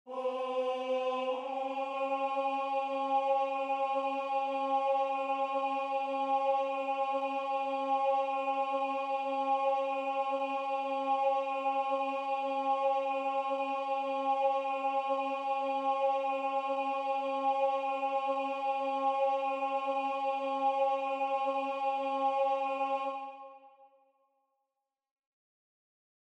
Key written in: C# Major
Type: Barbershop
Each recording below is single part only.